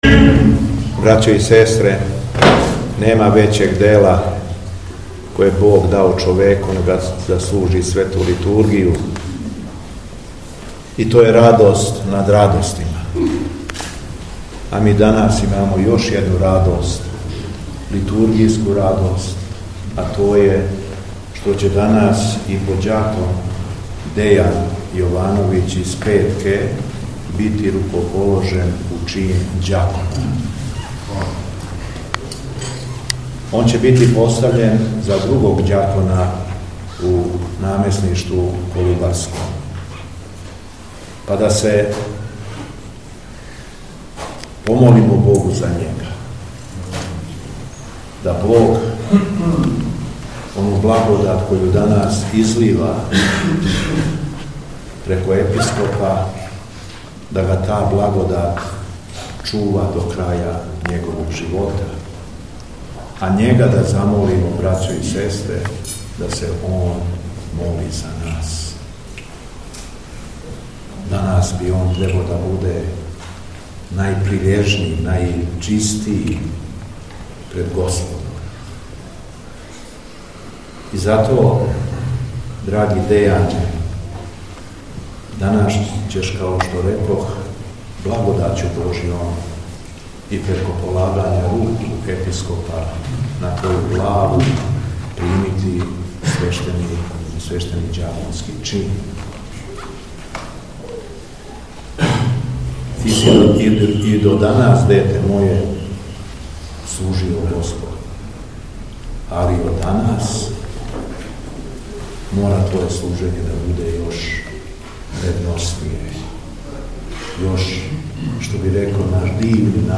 СВЕТА АРХИЈЕРЕЈСКА ЛИТУРГИЈА И РУКОПОЛОЖЕЊЕ У АРАПОВЦУ КОД ЛАЗАРЕВЦА - Епархија Шумадијска
Духовна поука Његовог Високопреосвештенства Митрополита шумадијског г. Јована